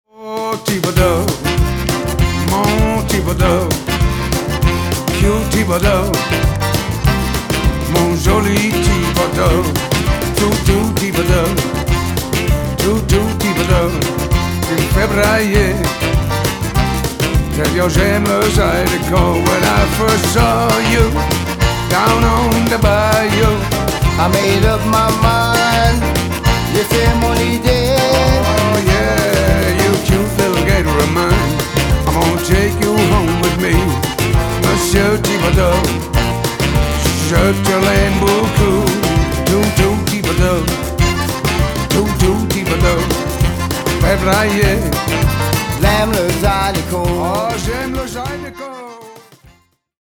PIANO, HAMMOND, WURLITZER
RECORDED AT LIMUSIC RECORDING STUDIOS, LIMOUX, FRANCE
RECORDED AT MALACO RECORDING STUDIOS, JACKSON, MISSISSIPPI